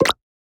menu-options-click.ogg